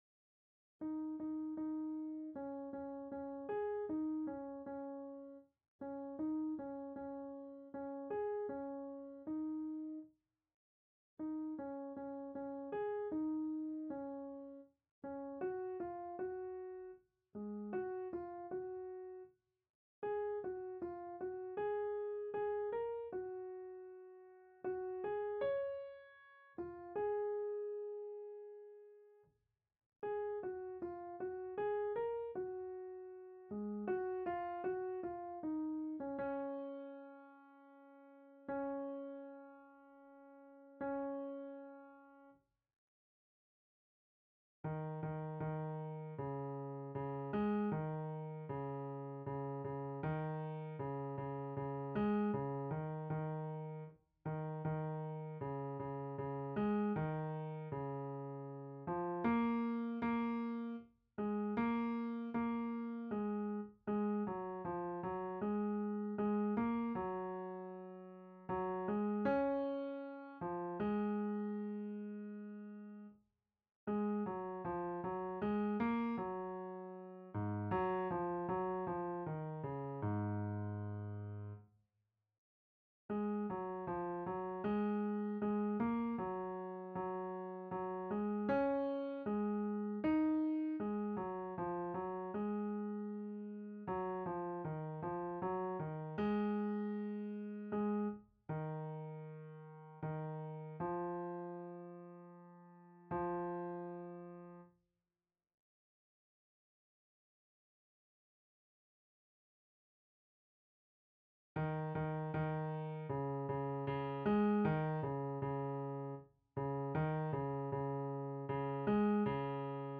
Bass part alone
Bass.mp3